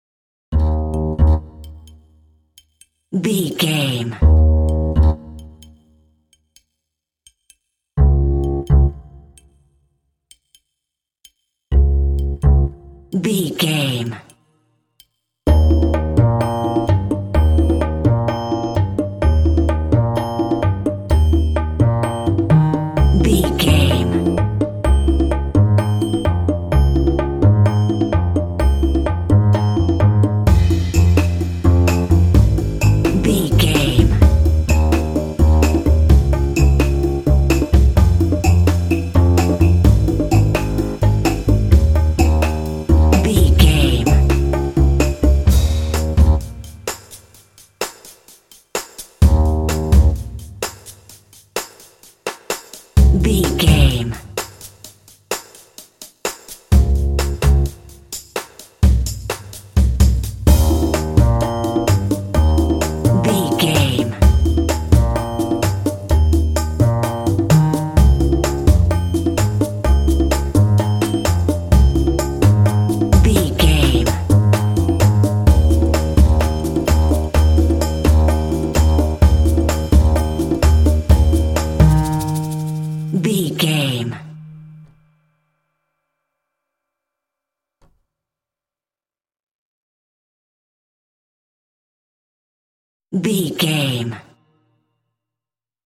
Ionian/Major
E♭
happy
uplifting
dreamy
relaxed
double bass
drums
percussion
contemporary underscore